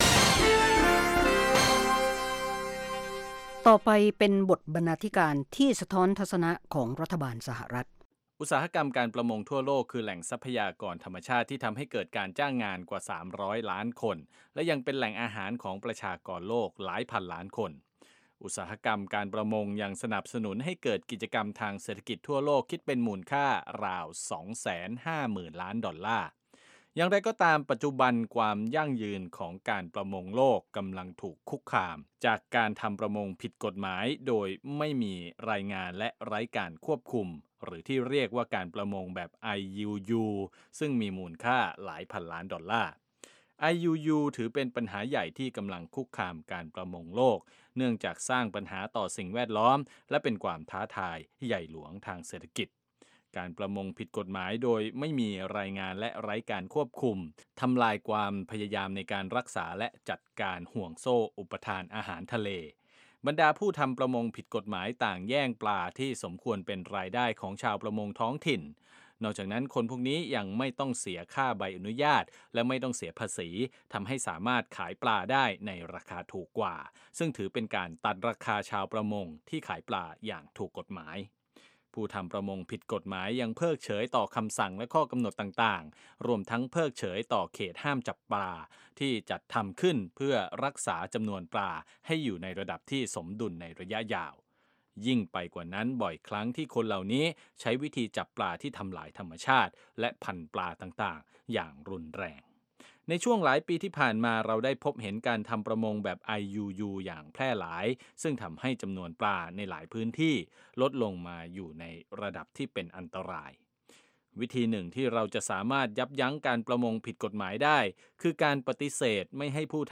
วาไรตี้โชว์ ฟังสบายๆ สำหรับวันหยุดสุดสัปดาห์ เริ่มด้วยการประมวลข่าวในรอบสัปดาห์ รายงานเกี่ยวกับชุมชนไทยในอเมริกา หรือเรื่องน่ารู้ต่างๆ ส่งท้ายด้วยรายการบันเทิง วิจารณ์ภาพยนตร์และเพลง